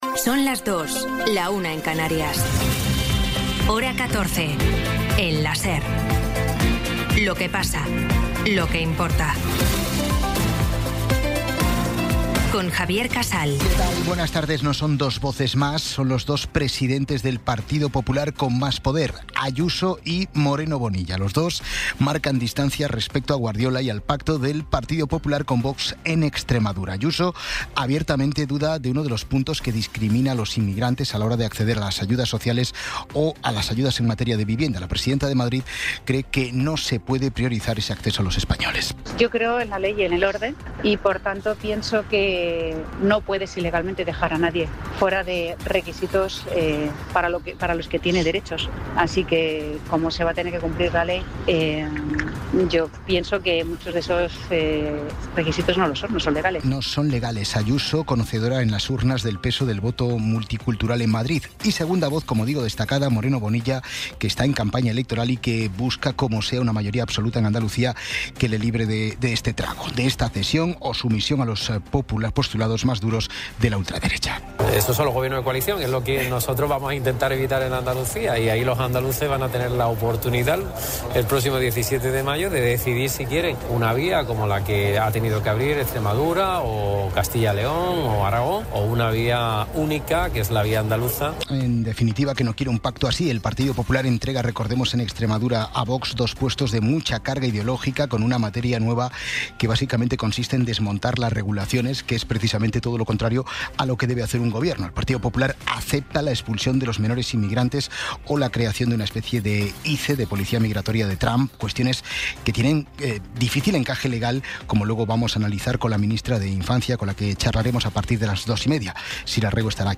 Resumen informativo con las noticias más destacadas del 17 de abril de 2026 a las dos de la tarde.